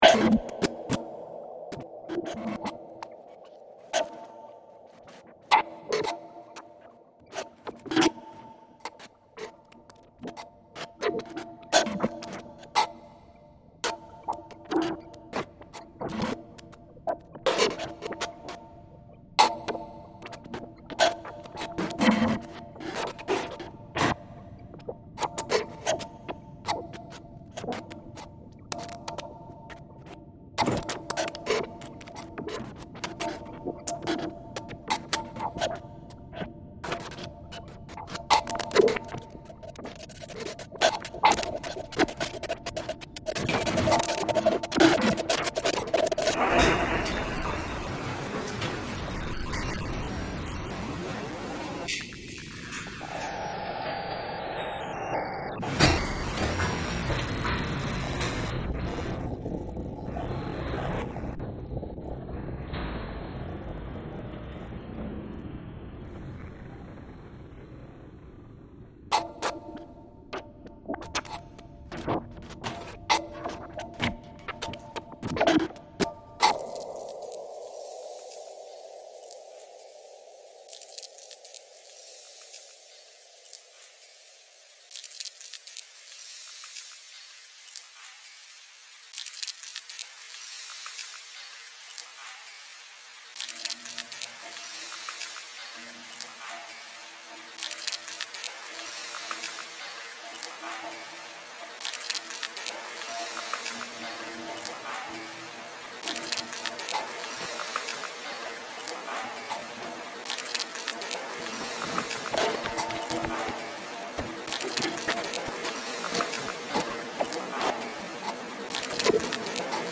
Montreal lockdown sound reimagined